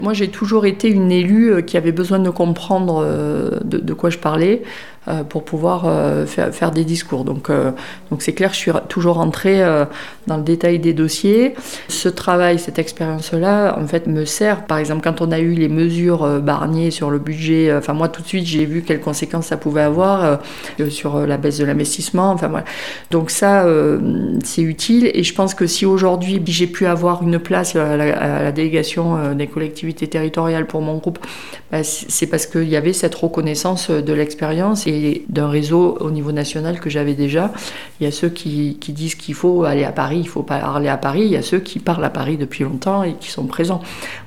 En ce début 2025, l’élue siégeant dans le groupe socialistes et apparentés a accordé une interview pour 48FM et tire un bilan de ces premiers mois en tant que parlementaire.